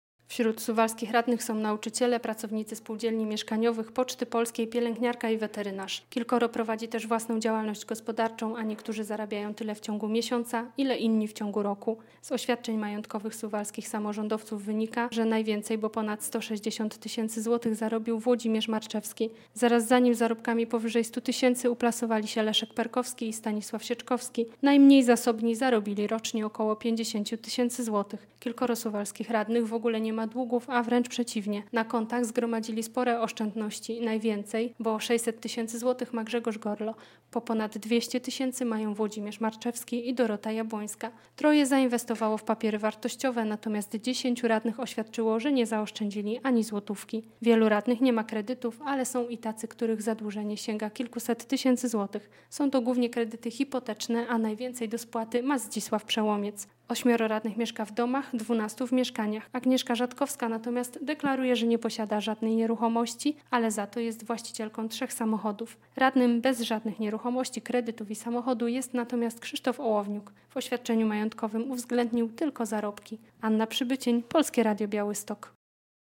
Majątki suwalskich radnych - relacja